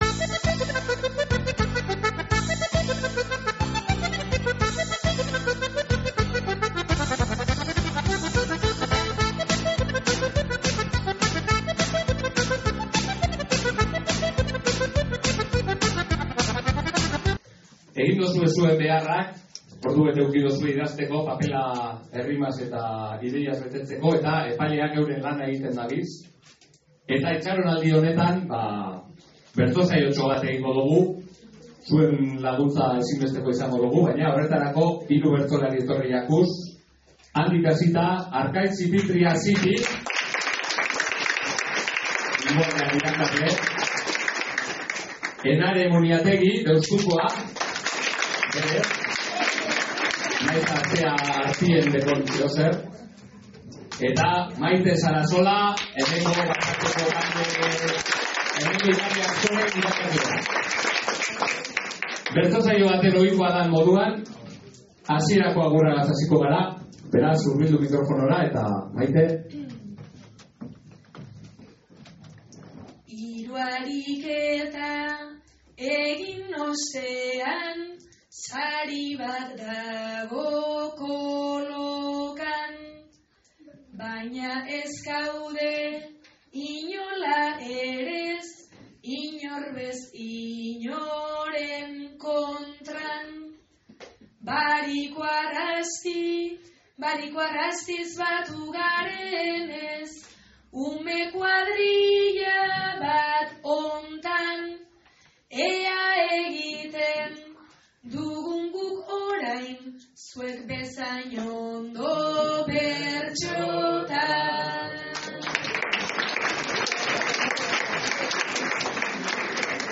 Idatzizko bertso txapelketa eta bat-bateko saioa izan dira topaketan